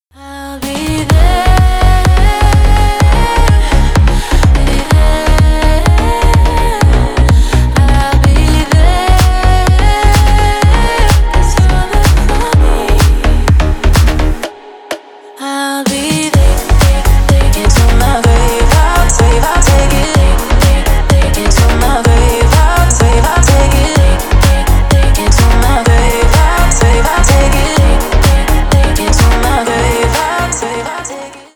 Stereo
Танцевальные
клубные громкие